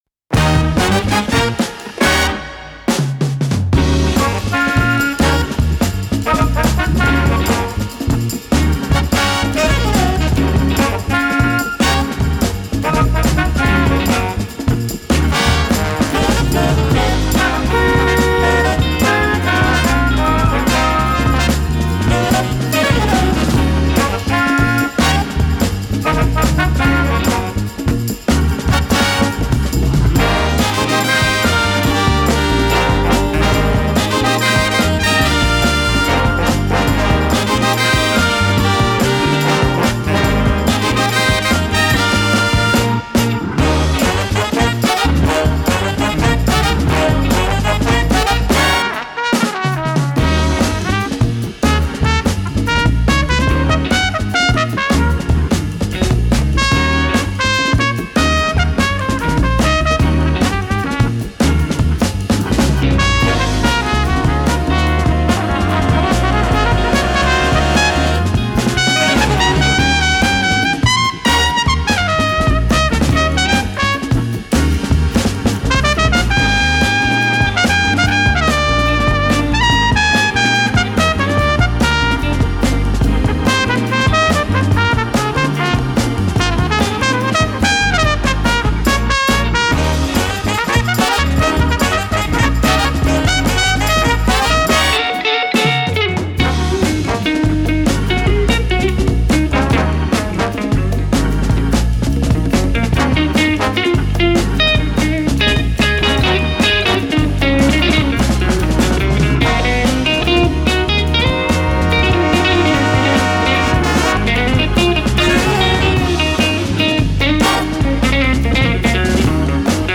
tenor saxophone
alto saxophone
baritone saxophone
trumpet
trombone
organ
percussion
drums